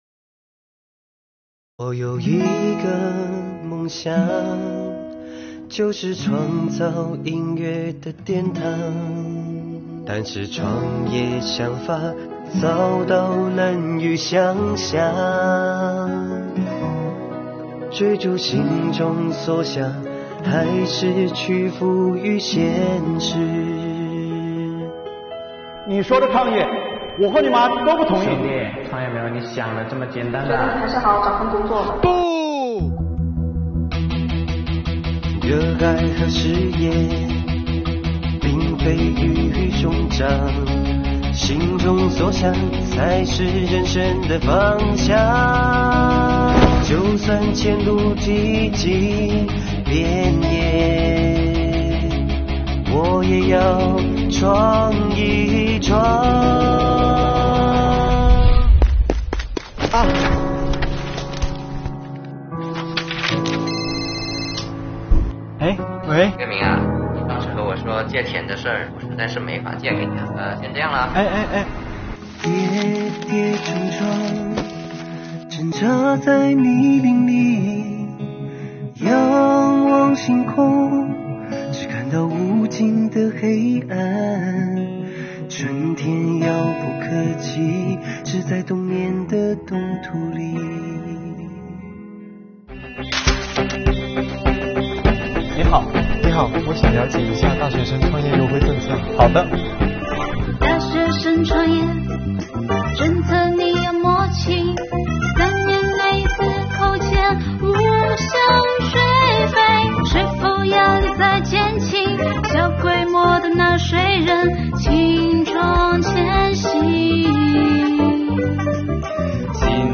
本片根据真实事件改编，以音乐剧的形式，讲述了一位热爱尤克里里的应届大学毕业生，在亲朋好友反对的情况下，毅然选择创建音乐教室的故事。在创业过程中，税收优惠政策“精准滴灌”，“春雨润苗”助力梦想起航。
本作品用音乐剧的形式，以大学生的视角介绍应届毕业生创业税收优惠政策，令人眼前一亮。